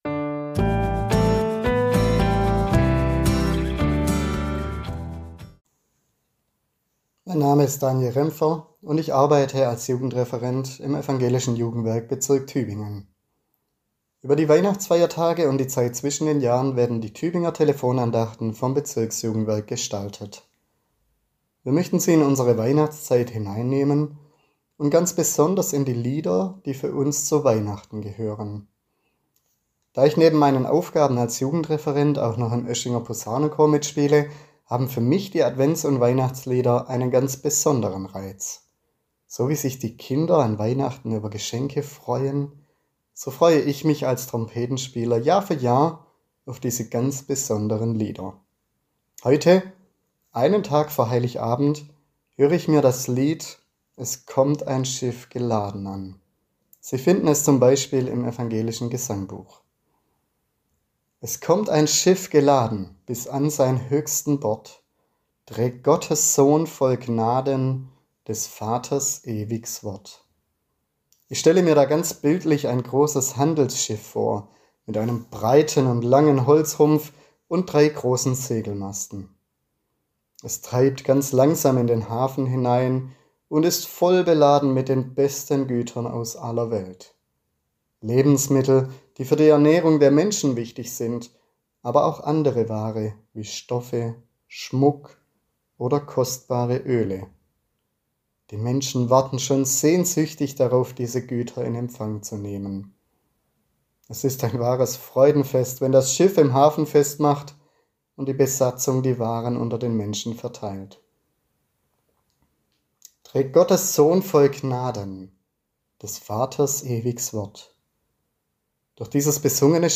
Andacht in der Weihnachtswoche